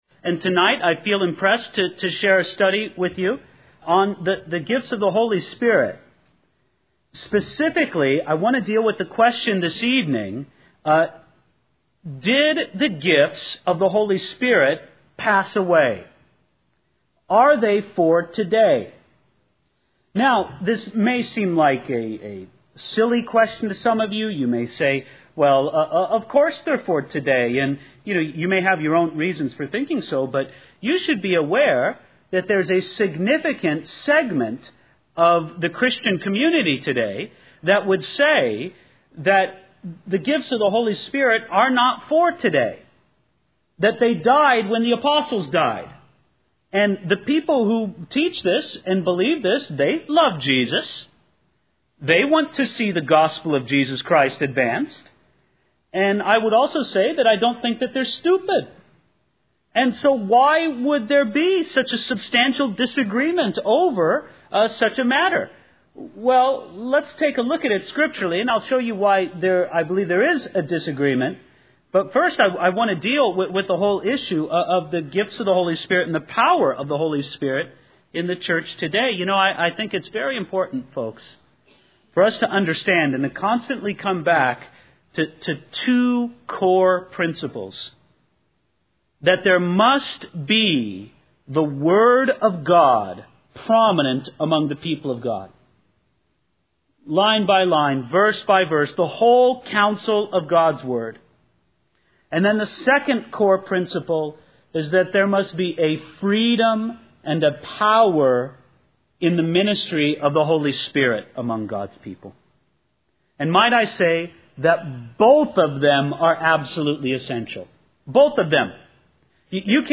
In this sermon, the speaker emphasizes the importance of paying close attention to the word of God and not drifting away from it.